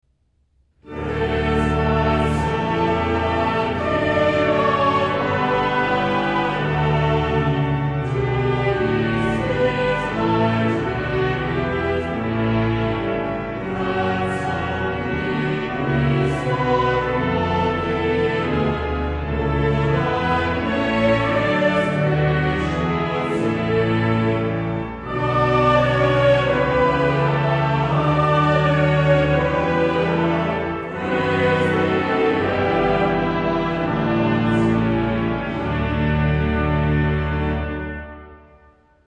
Congregational Hymns: